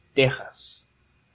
Spanish pronunciation of "Texas"
ES-pe_-_Texas.ogg.mp3